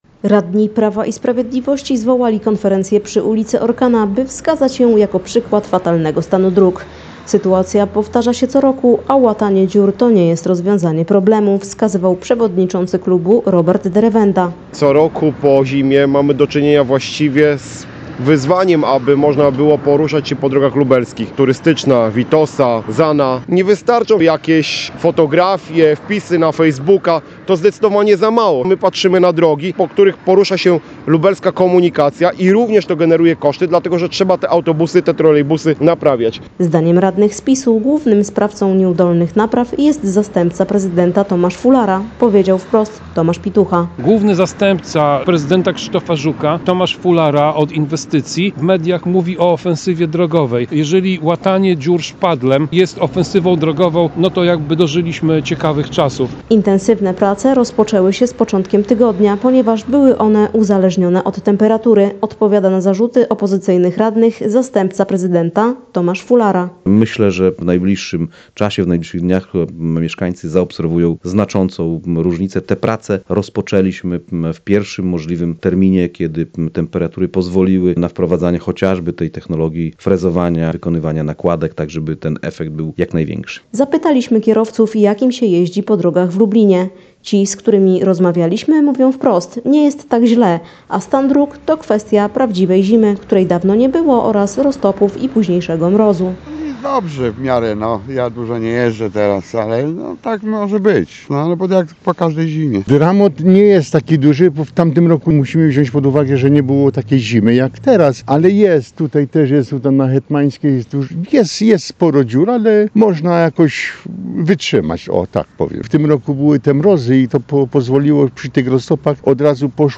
Zapytani przez nas kierowcy mówią wprost – dziury są, ale nie ma dramatu, biorąc pod uwagę zimę w tym sezonie. Z kolei urzędnicy odpowiadają na zarzuty opozycyjnych radnych, że sprawą ubytków zajęli się niezwłocznie po tym, gdy pierwsze prace umożliwiły temperatury.